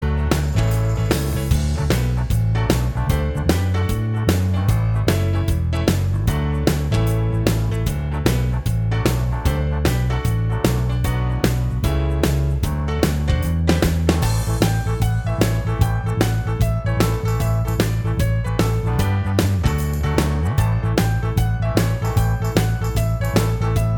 Minus All Guitars Pop (1970s) 3:25 Buy £1.50